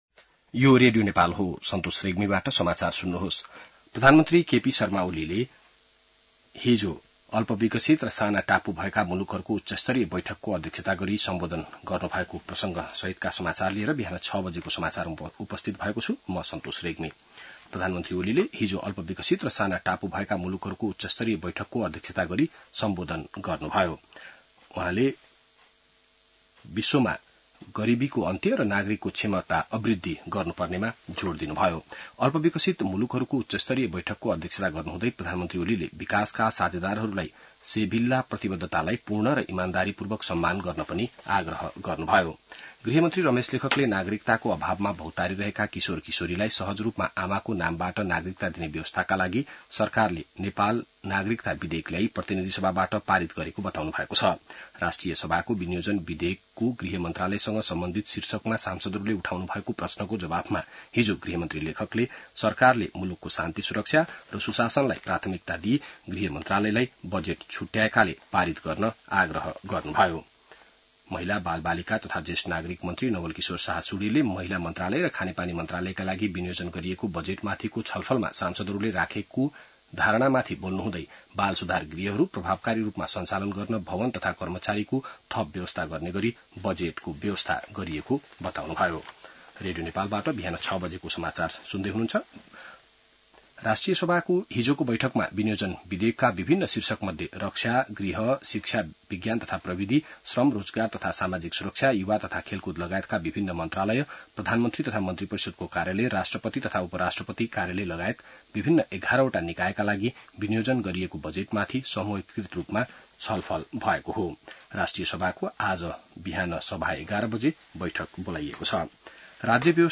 बिहान ६ बजेको नेपाली समाचार : १८ असार , २०८२